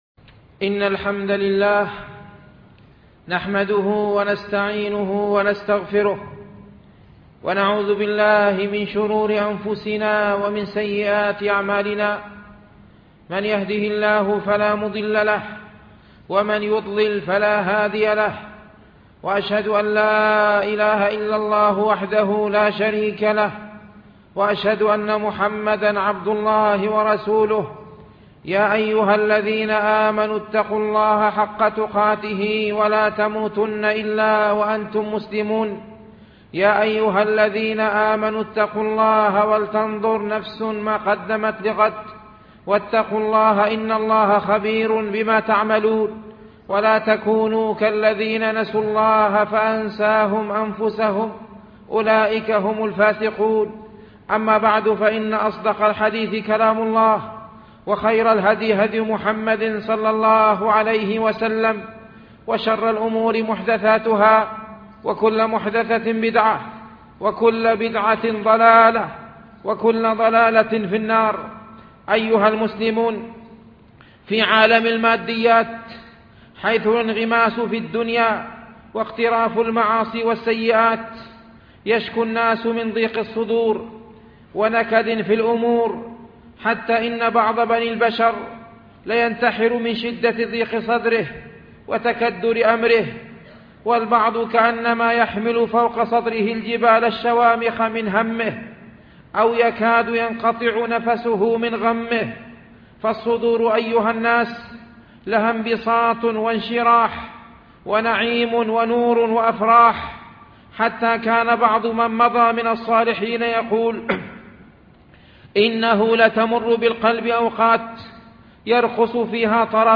خطبة
MP3 Mono 11kHz 32Kbps (CBR)